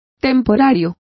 Complete with pronunciation of the translation of temporary.